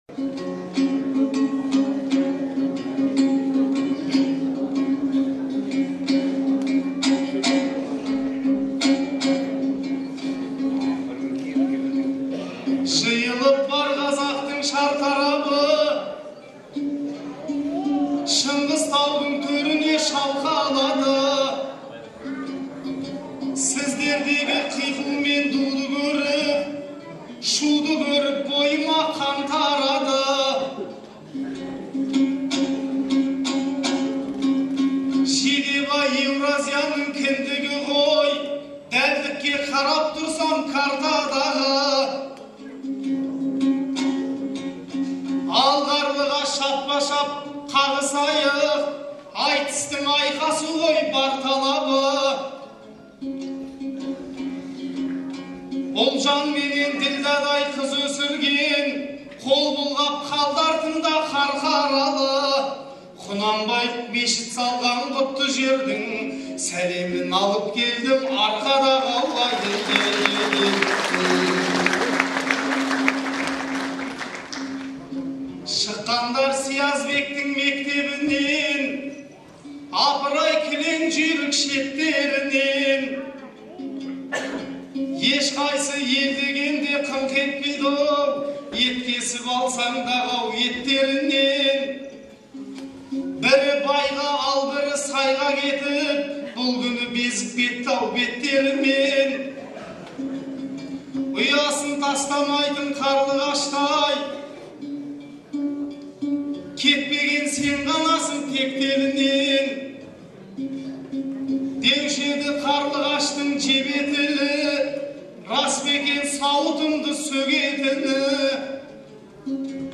Қыркүйектің 15-і күні Шығыс Қазақстан облысы Абай ауданының орталығы Қарауылда «Бабалар тойы – ел тойы» деген атпен Көкбай Жанатайұлы мен Ақылбайдың туғанына 150 жыл, Шәкір Әбеновтің туғанына 110 жыл толуына орай республикалық айтыс өтті.